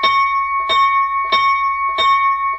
鐘のマークをクリックすると、本会議・委員会の始まりの合図として鳴らされる鐘の音が聞けます。
音声：本会議の合図 音声：委員会の合図
bell01.wav